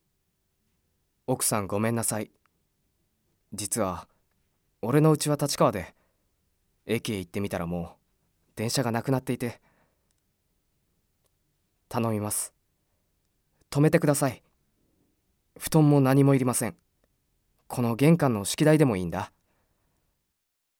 ボイスサンプル
セリフA